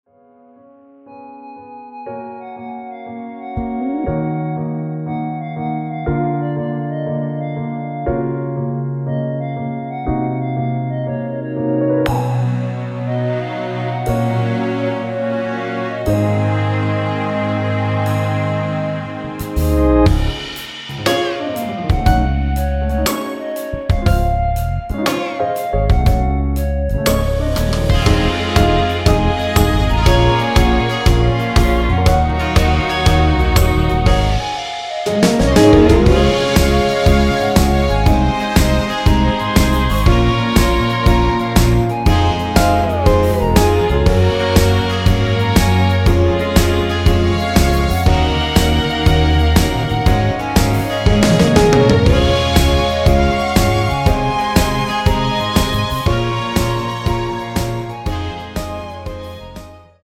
여성분이 부르실 수 있는 키의 MR입니다.
원키에서(+3)올린 멜로디 포함된 MR입니다.(미리듣기 확인)
C#
앞부분30초, 뒷부분30초씩 편집해서 올려 드리고 있습니다.